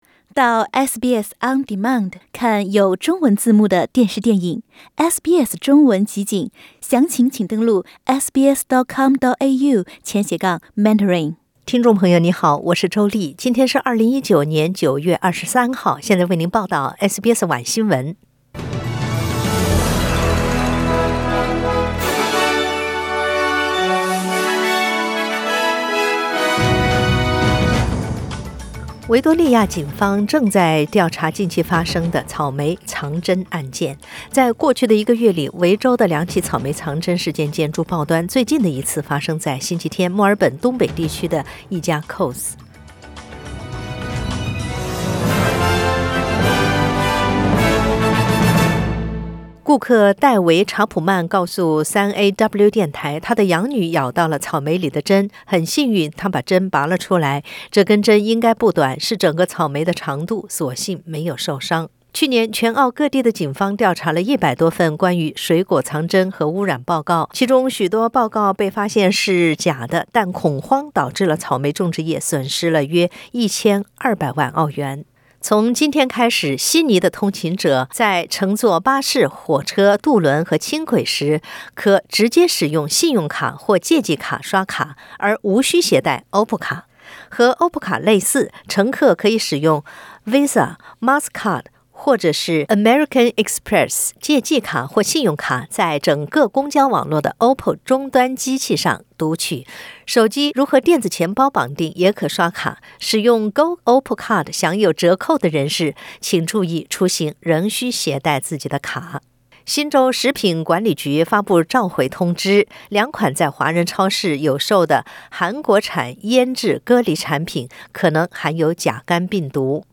SBS 晚新闻 （9月23日）